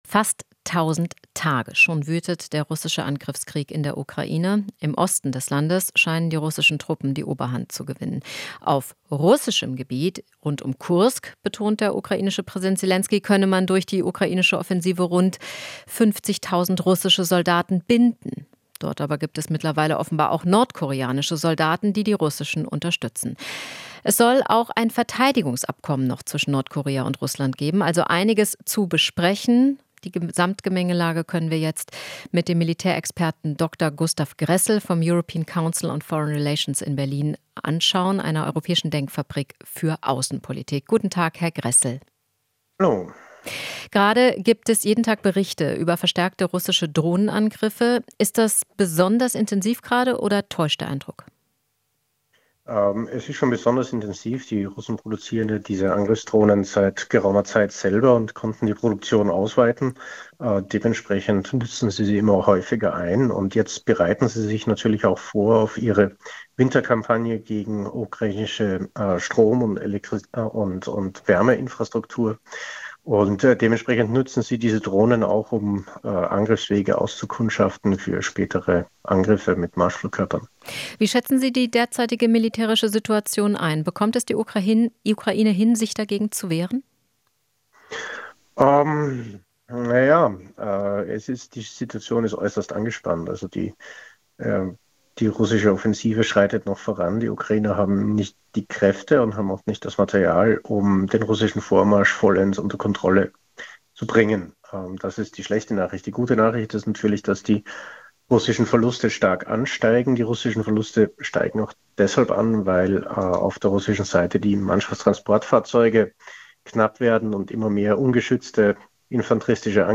Interview - Militärexperte: Ukraine braucht Unterstützung der USA